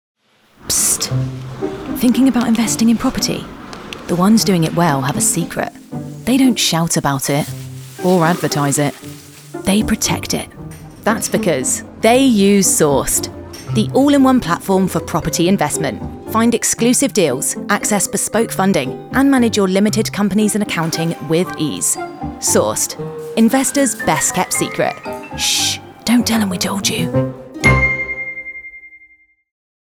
Relatable, Youthful, Energetic, Warm, Conversational, Authentic